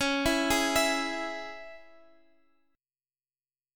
Listen to C#m strummed